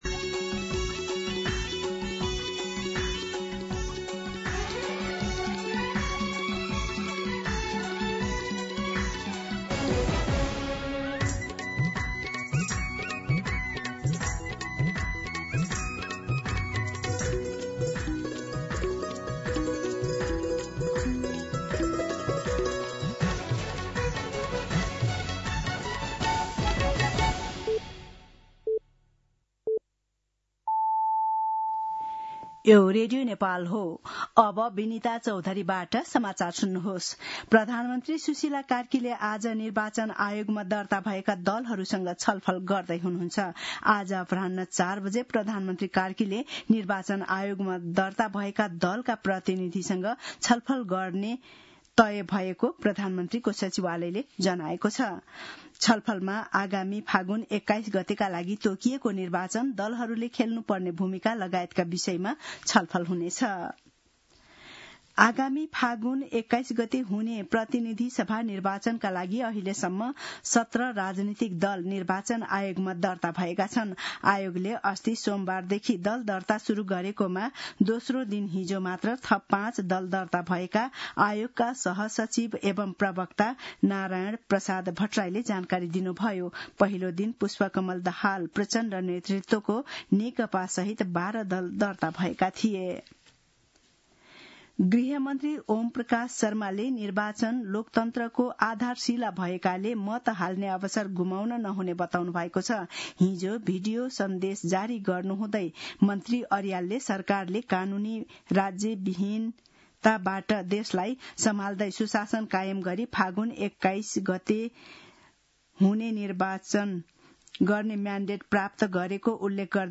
दिउँसो १ बजेको नेपाली समाचार : १८ पुष , २०२६
1-pm-Nepali-News-4.mp3